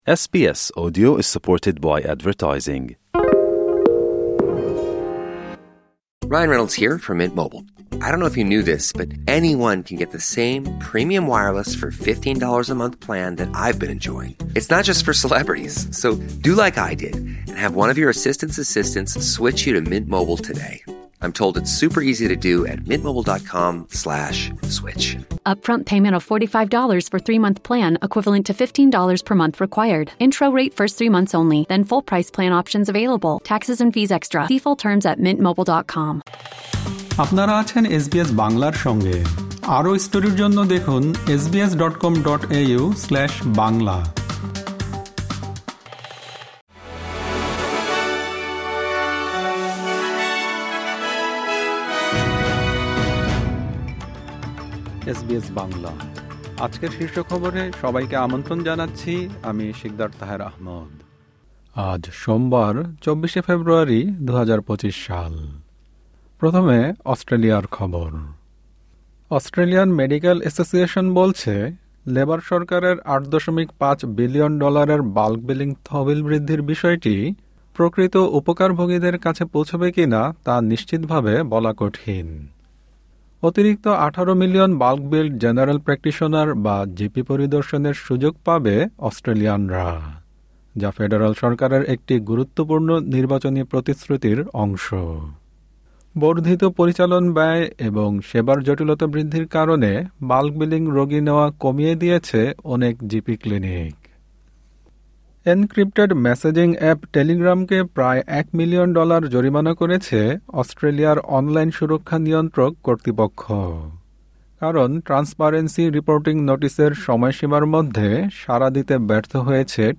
এসবিএস বাংলা শীর্ষ খবর: ২৪ ফেব্রুয়ারি, ২০২৫